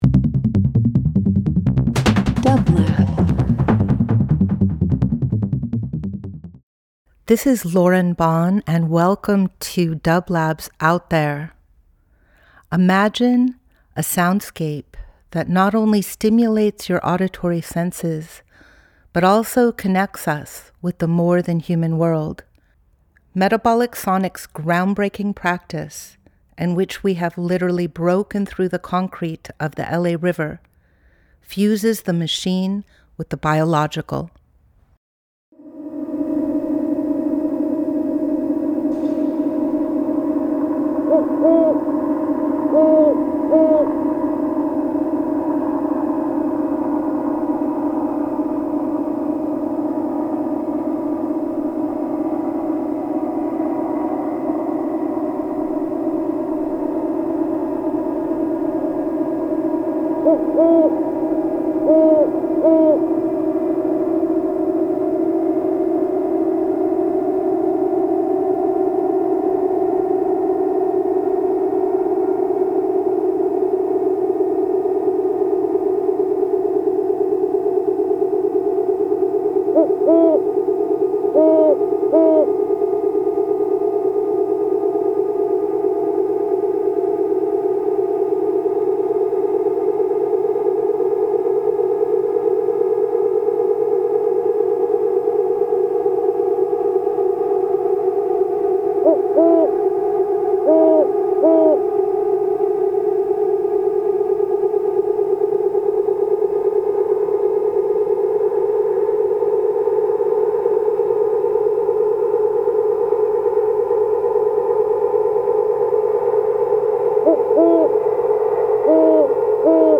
Each week we present field recordings that will transport you through the power of sound.
Metabolic Sonics Metabolic Studio Out There ~ a field recording program 02.19.26 Ambient Experimental Field Recording Voyage with dublab into new worlds.
This is a field recording of the owls that live in a silo strung with a massive Aeolian Harp located next to the Owens Lake.